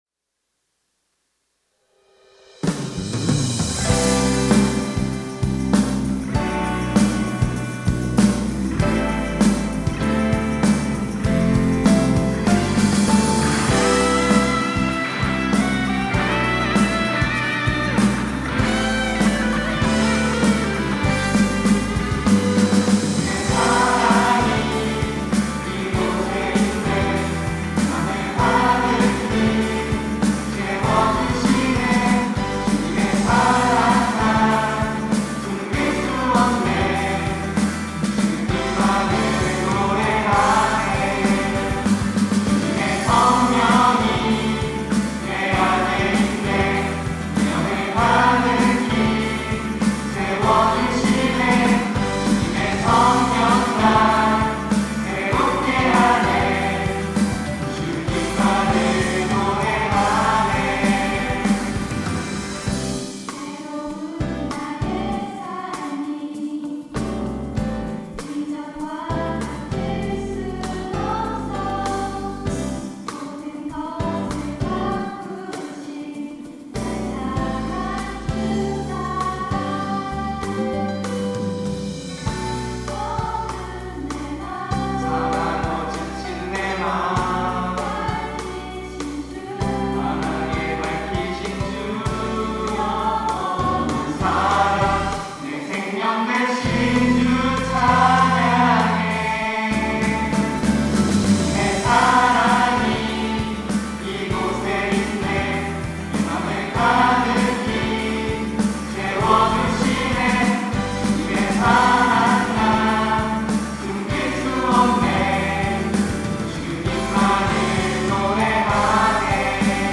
특송과 특주 - 주님의 사랑이 이 곳에 있네
청년부 예수손길